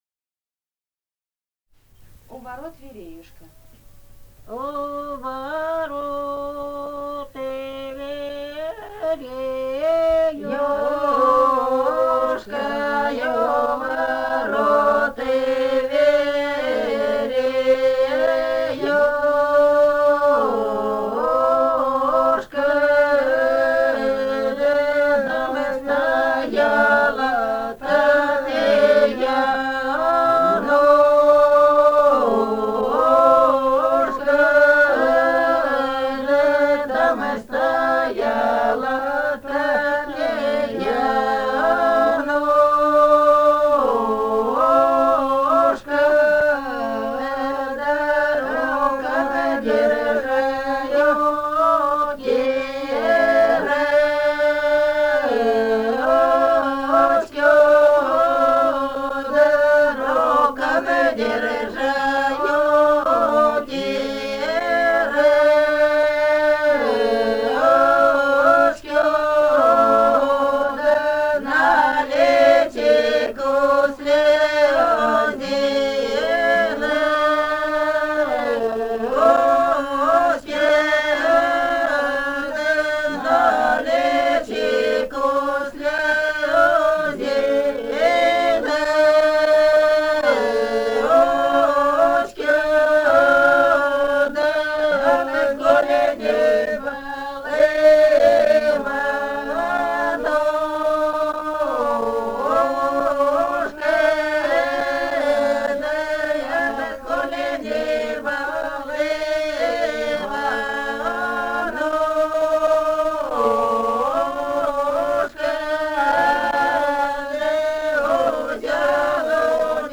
полевые материалы
«У ворот вереюшка» (свадебная).
Алтайский край, с. Михайловка Усть-Калманского района, 1967 г. И1001-16б